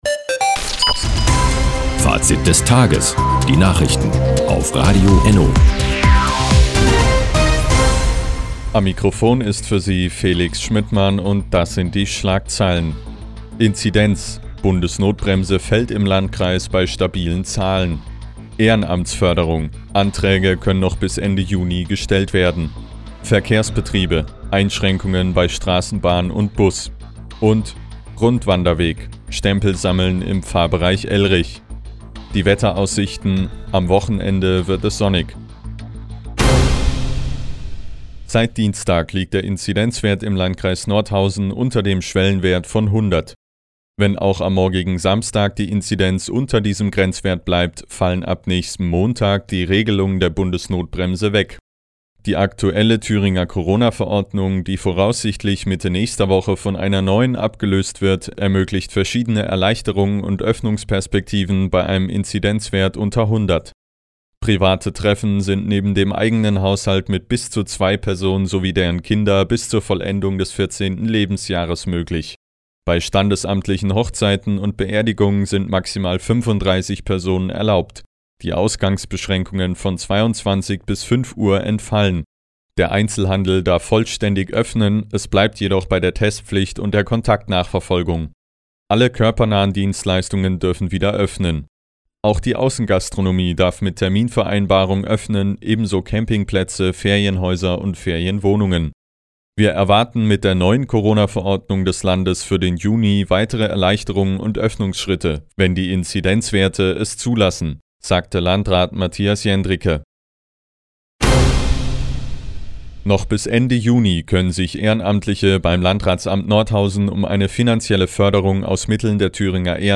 Fr, 16:30 Uhr 28.05.2021 Neues von Radio ENNO Fazit des Tages Anzeige symplr (1) Seit Jahren kooperieren die Nordthüringer Online-Zeitungen und das Nordhäuser Bürgerradio ENNO. Die tägliche Nachrichtensendung ist jetzt hier zu hören.